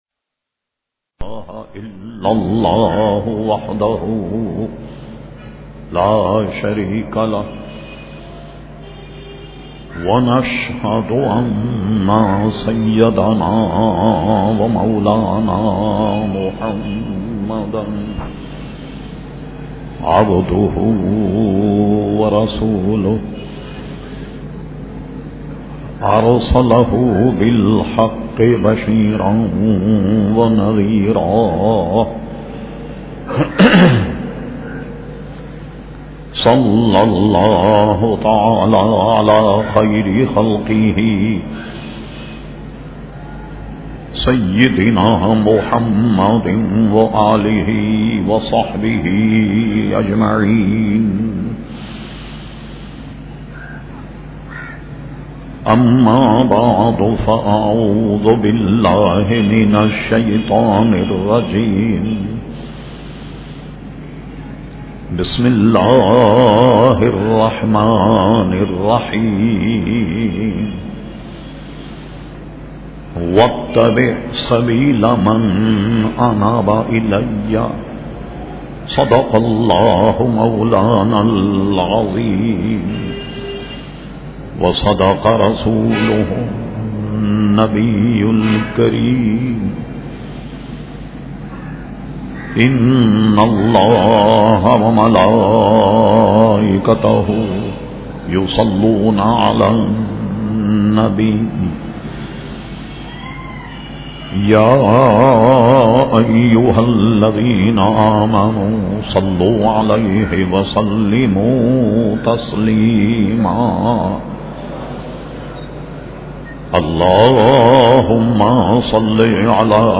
تقاریر